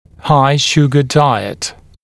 [haɪ-‘ʃugə ‘daɪət][хай-‘шугэ ‘дайэт]диета с высоким содержанием сахара